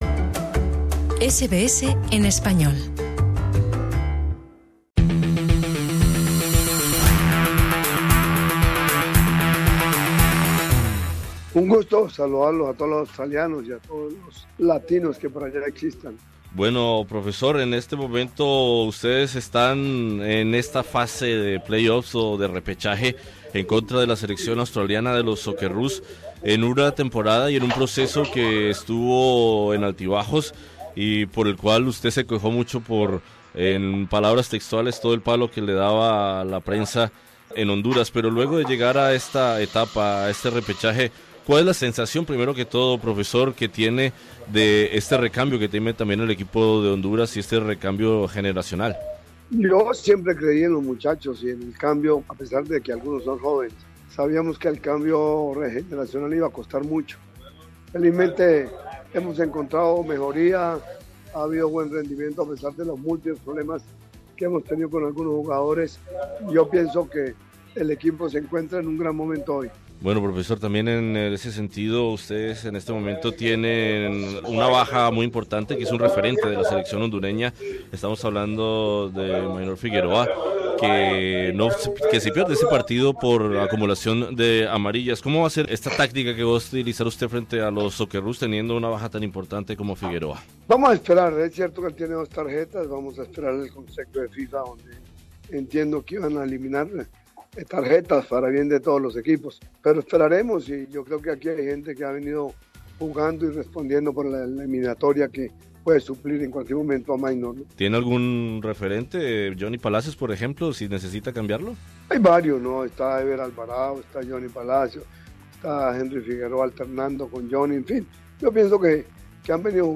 Entrevistamos en exclusiva al técnico de la selección de Honduras, el colombiano Jorge Luis Pinto.